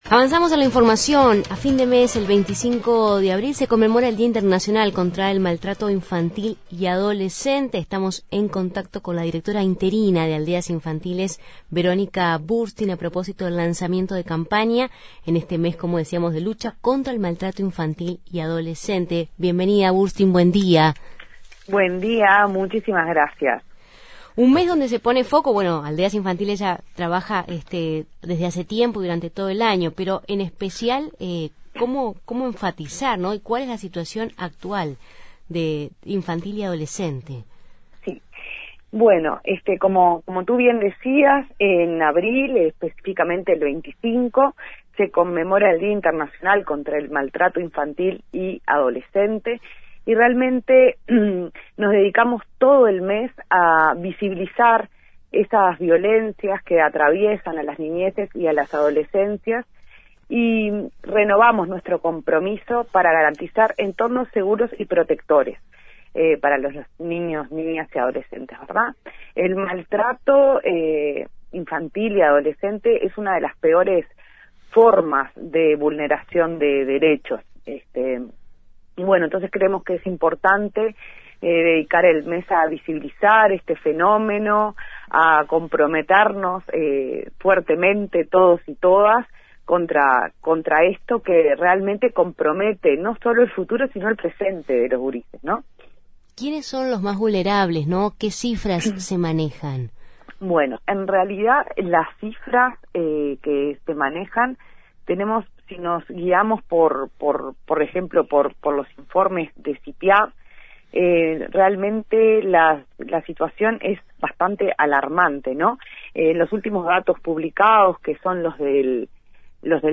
Entrevistada por Justos y Pecadores